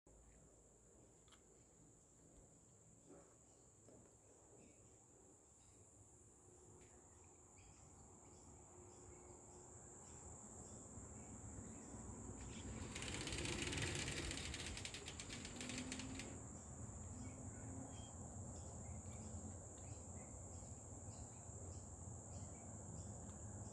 Kookaburra On Our Patio Table Looking For Food